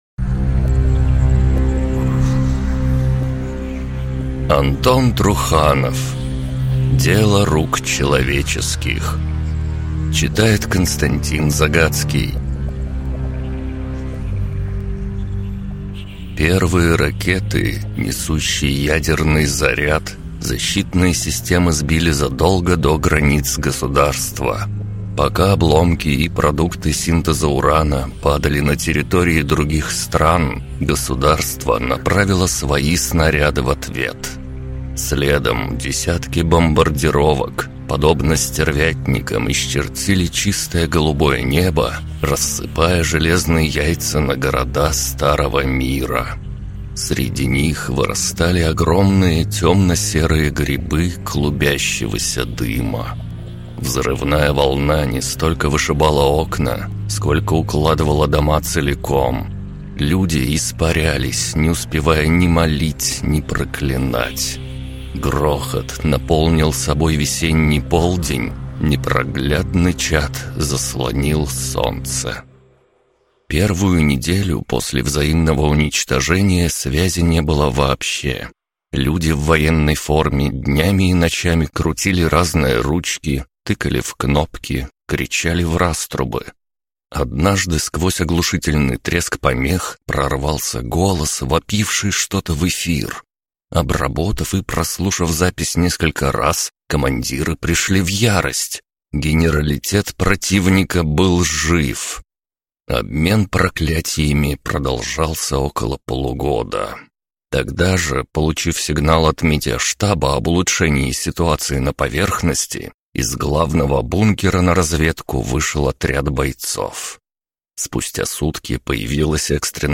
Аудиокнига Дело рук человеческих | Библиотека аудиокниг